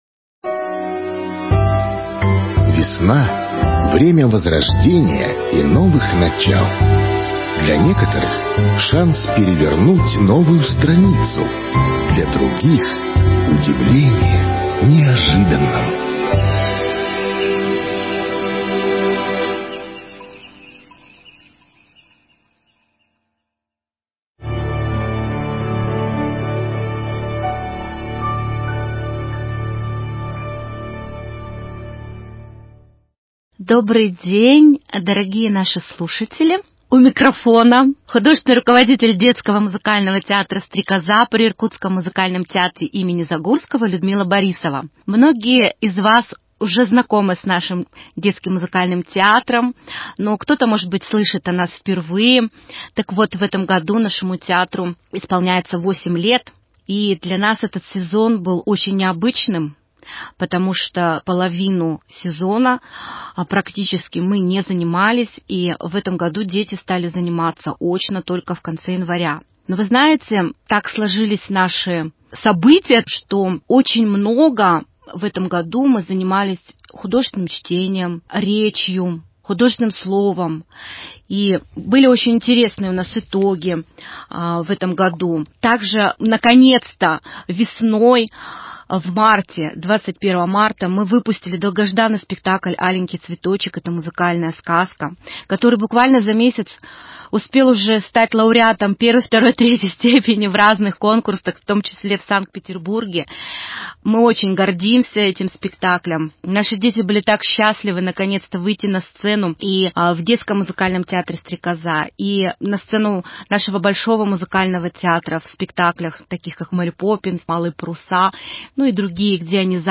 В праздник День защиты детей – участник передачи детский музыкальный театр «Стрекоза» при Музыкальном театре им. Н.М. Загурского. Коллектив представляет вашему вниманию творческое прощание с весной.